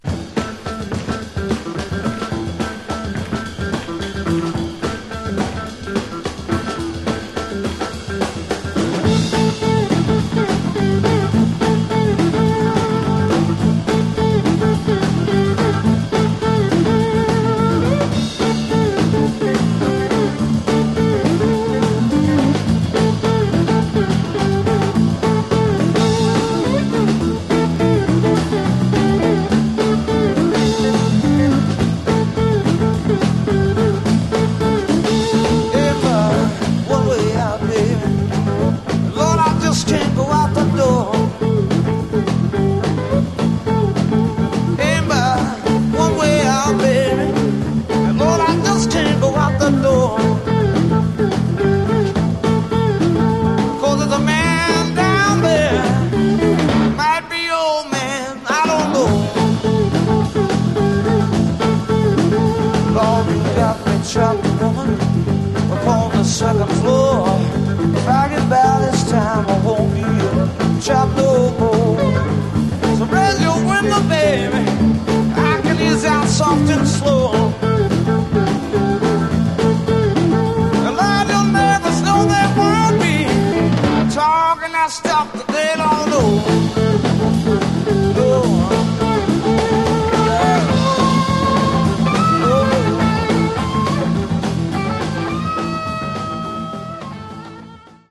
Genre: Southern Rock